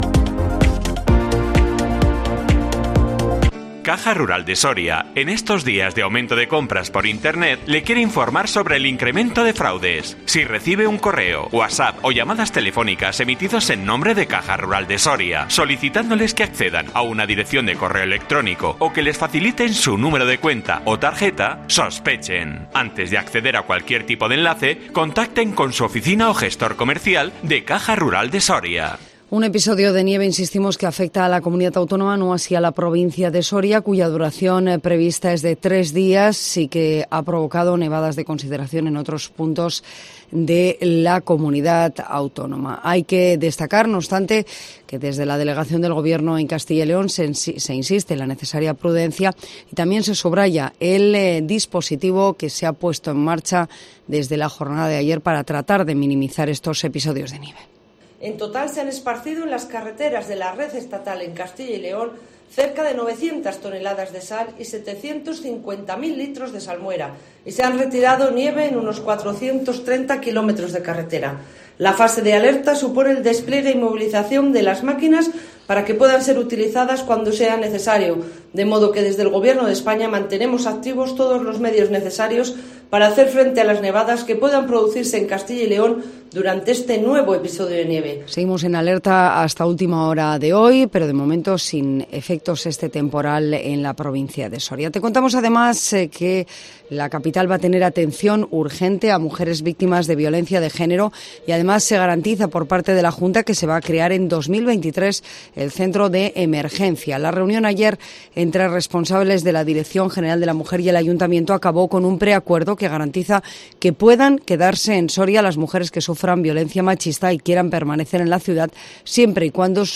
INFORMATIVO MEDIODÍA COPE SORIA 27 ENERO 2023